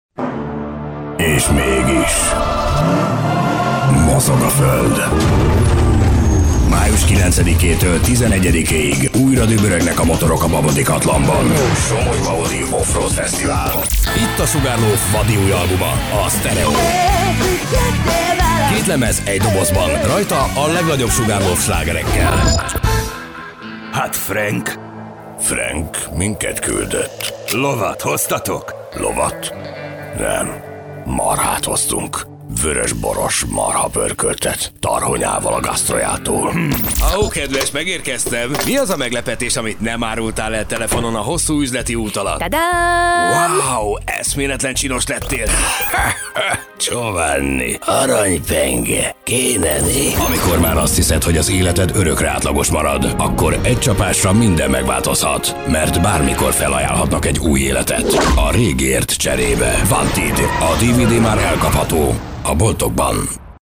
deep, cool, wry, energetic, announcer, sturdy, authoritative, corporate,
Sprechprobe: Industrie (Muttersprache):